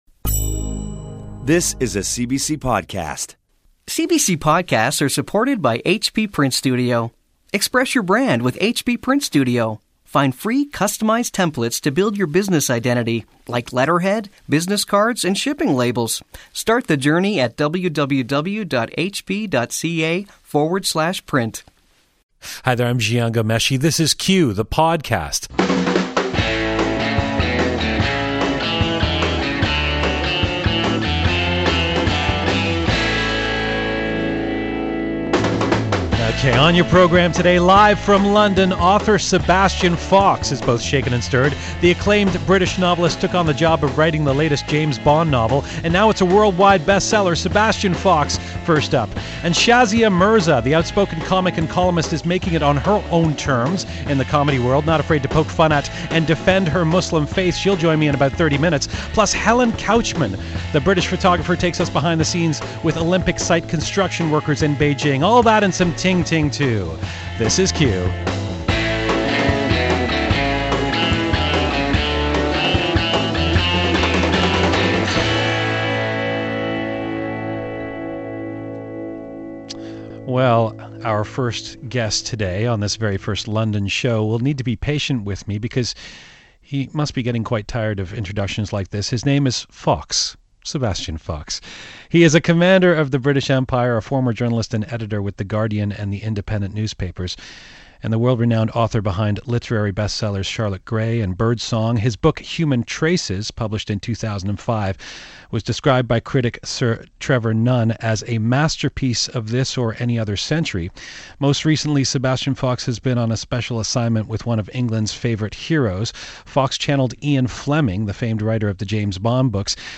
Published 2008 ORDER BOOKS HERE Interview for BBC Radio Canada Review of WORKERS in The New Yorker Slideshow about the WORKERS project for The Age